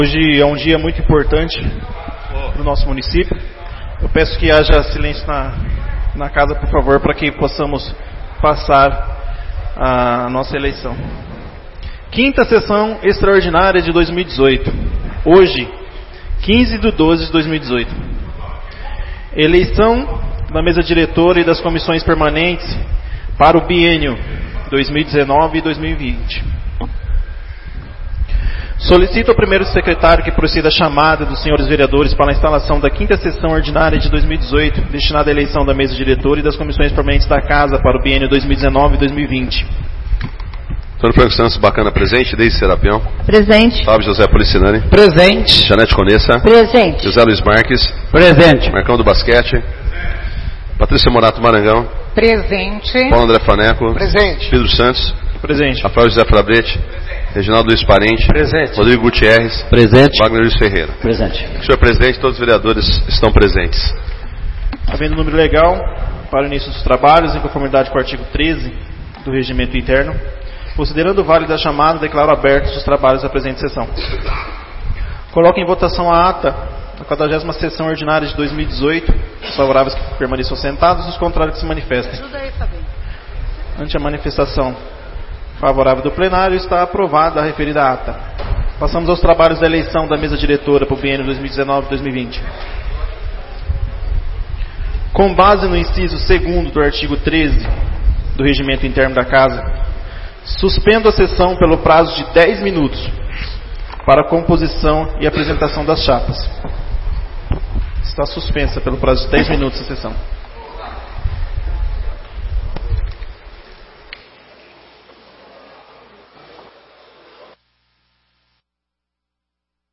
5ª Sessão Extraordinária de 2018